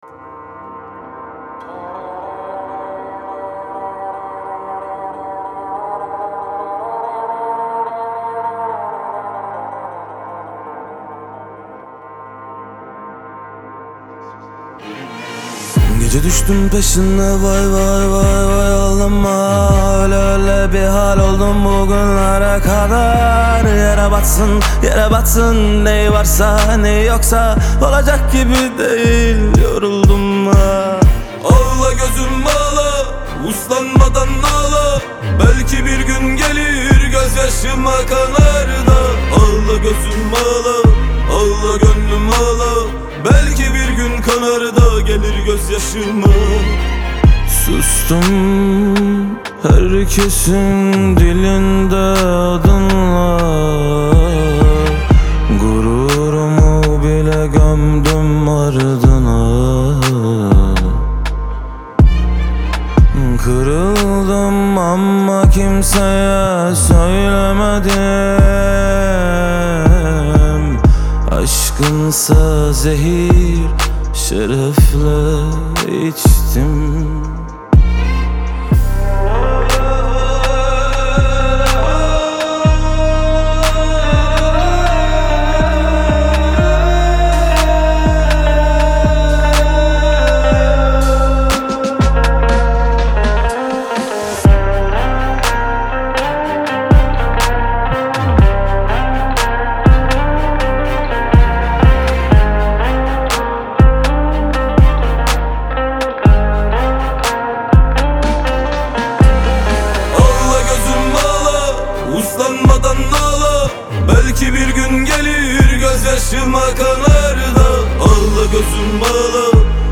Трек размещён в разделе Турецкая музыка.